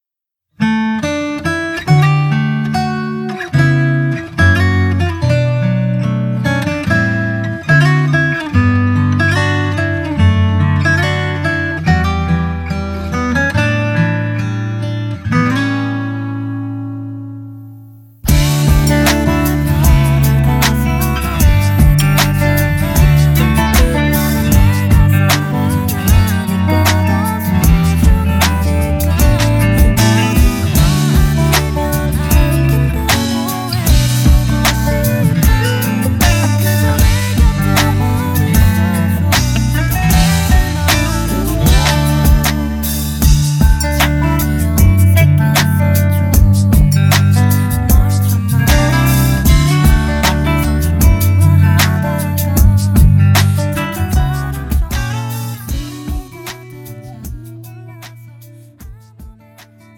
음정 -1키 3:37
장르 가요 구분 Voice MR
가사 목소리 10프로 포함된 음원입니다